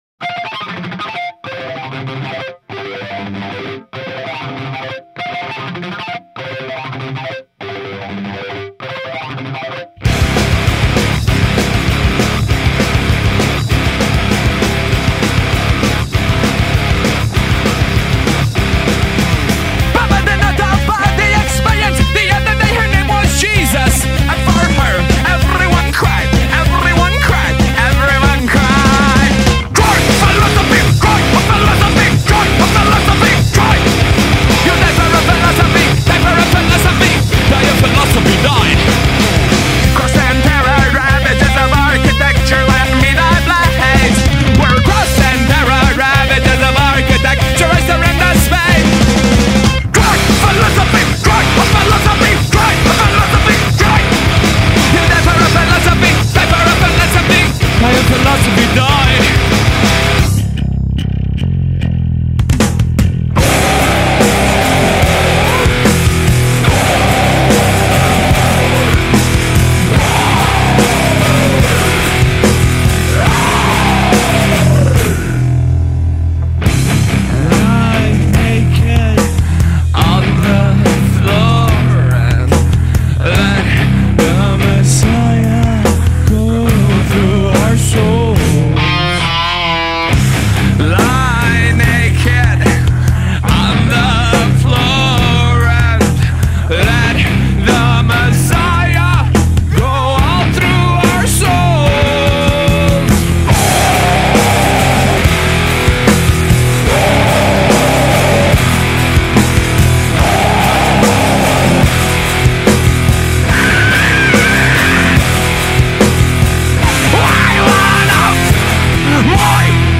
Alternative Metal / Nu Metal